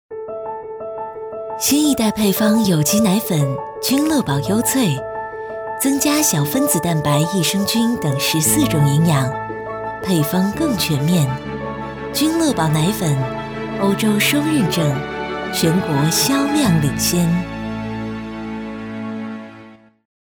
女29-奶粉广告 君乐宝
女29-大气磁性 科技时尚
女29-奶粉广告 君乐宝.mp3